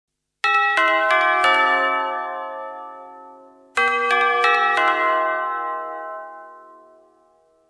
Мелодії і звуки для СМС повідомлень